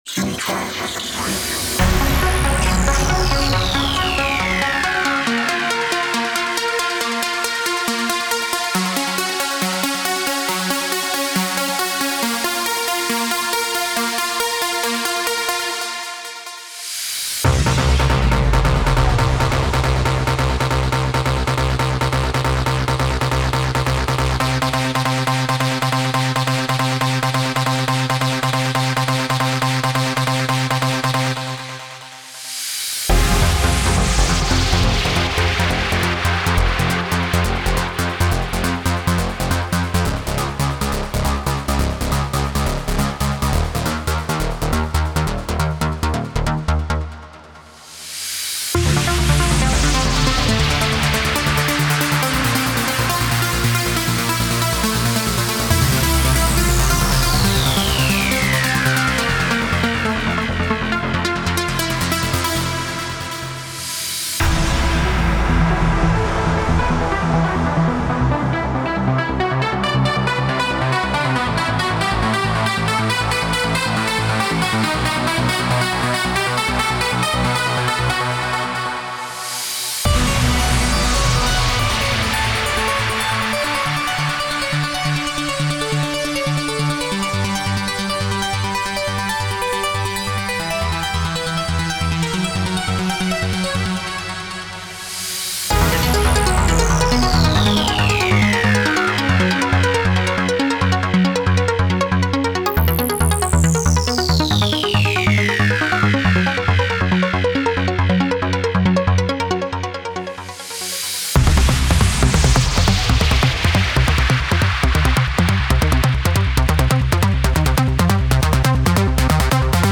Trance Uplifting Trance